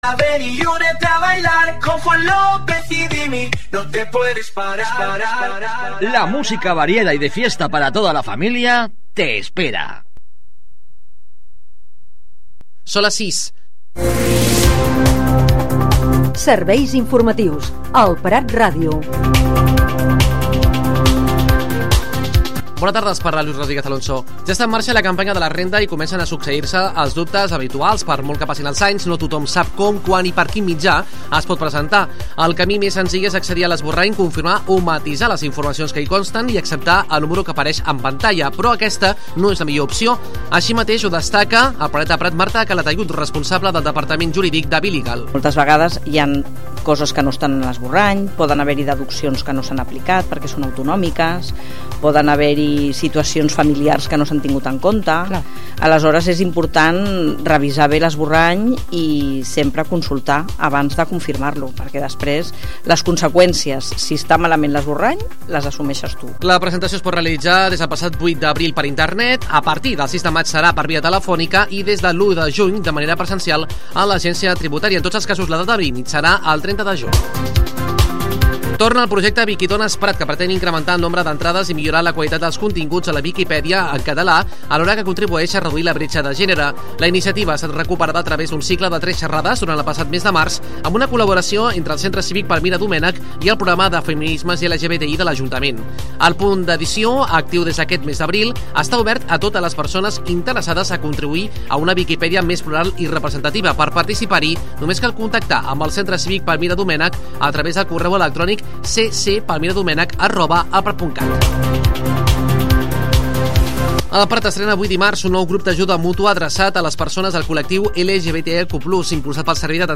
Butlletí de les 18:00 h
Espai informatiu d'elprat.ràdio, amb tota l'actualitat local i de proximitat.